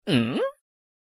常用抖音疑问效果音效_人物音效音效配乐_免费素材下载_提案神器